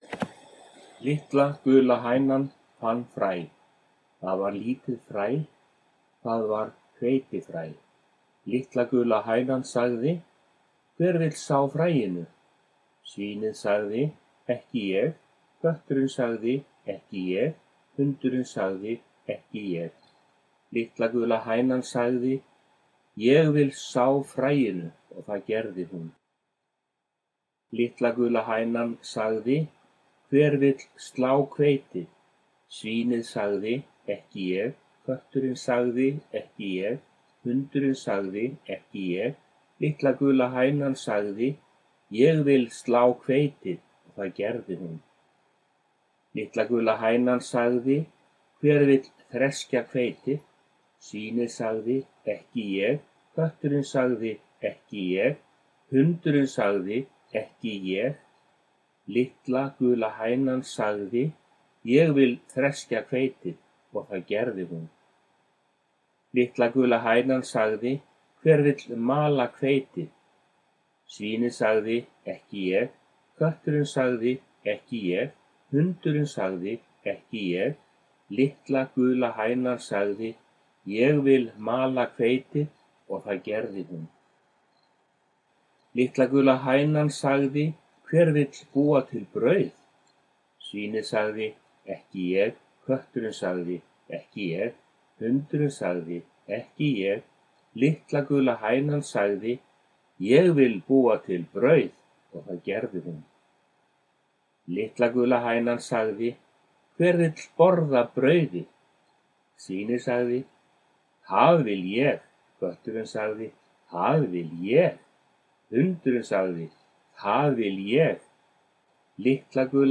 Øvelse - lese - Litla gula hænan
Teksten er bra for de som har problemer med å uttale h foran v (hver, hvað) og både é og g i ég (ég - jech).